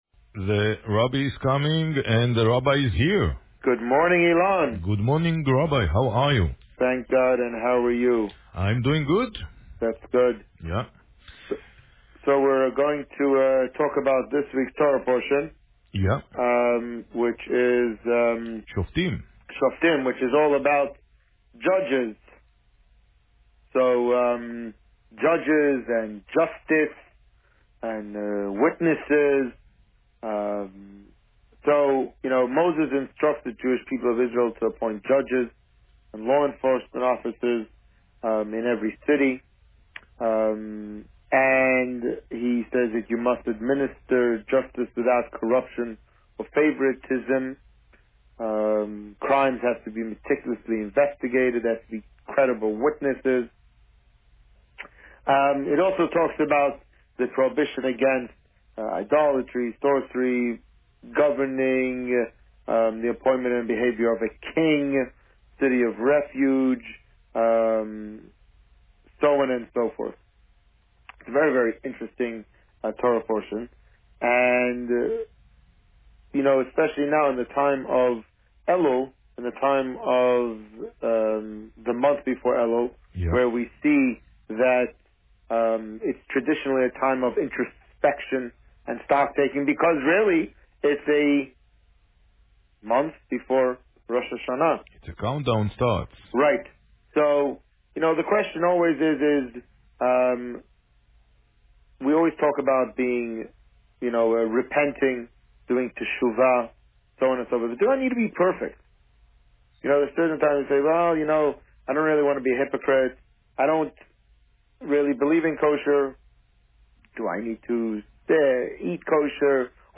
Last week, and again this week, the Boker Tov show has featured a rerun of last year's Parsha Shoftim interview, recorded on August 24, 2017.